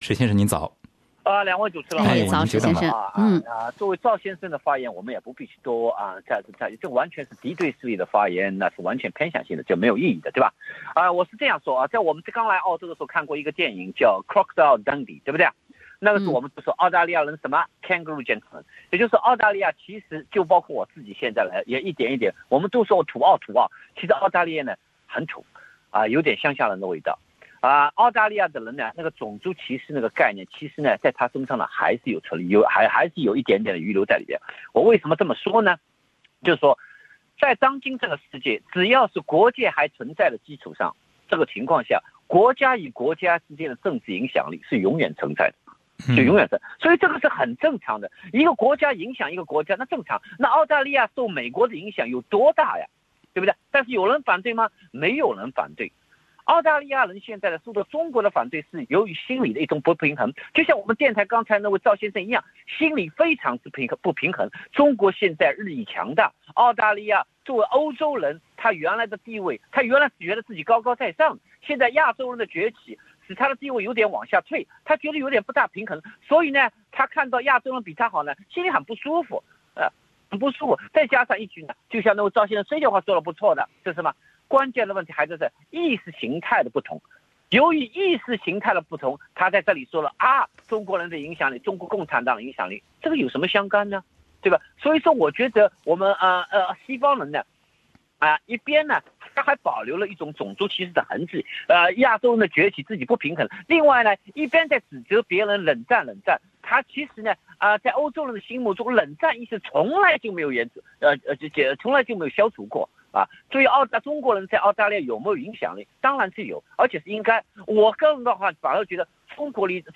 本台正在行动节目的一些听众在热线中表达了自己的观点，认为澳大利亚反对中国在澳洲的影响力是一种偏见。